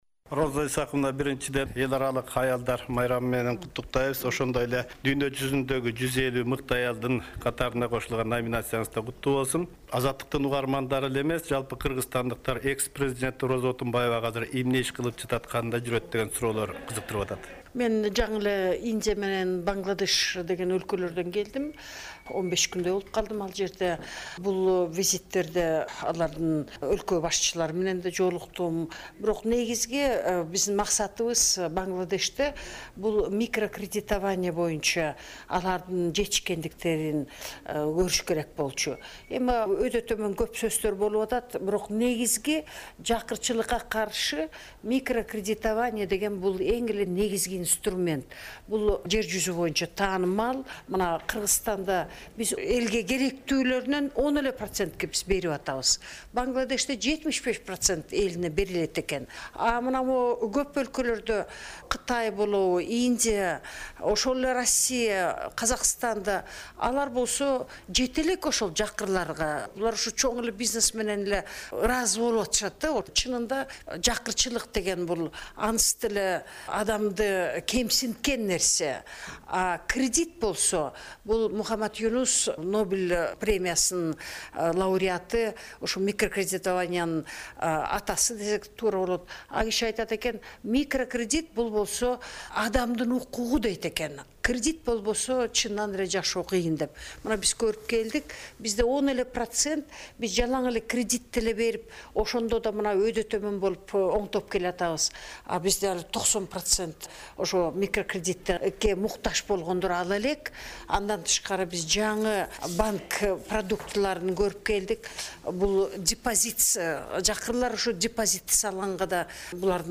Роза Отунбаева менен маек